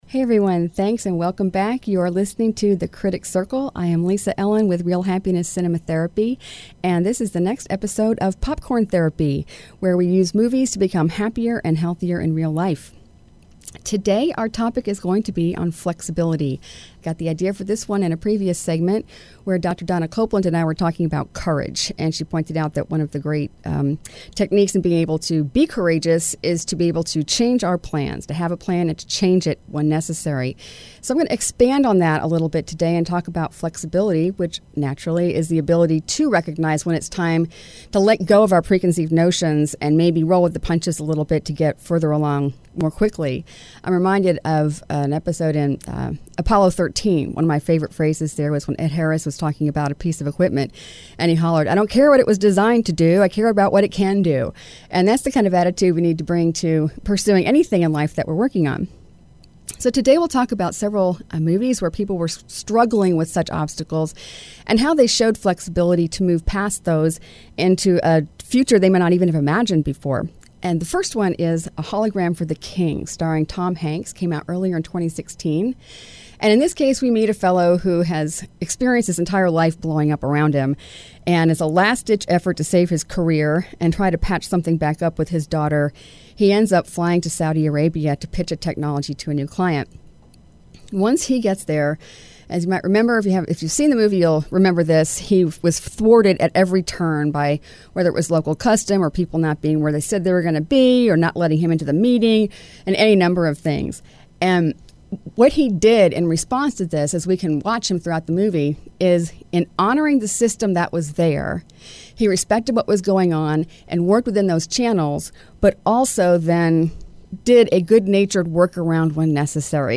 Currently on hiatus, The Critics Circle was the weekly radio show from the Houston Film Critics Society.